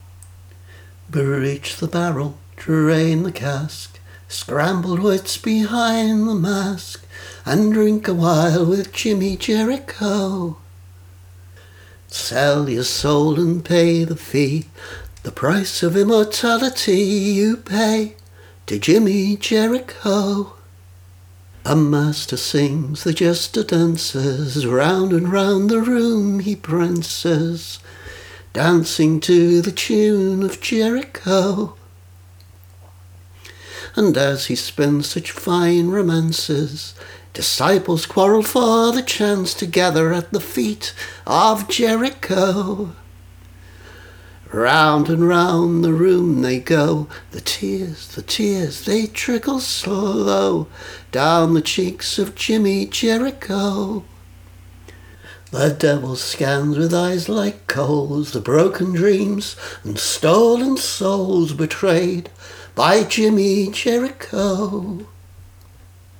I haven’t tried this with guitar yet, as I wanted to get the tune down before I forgot it…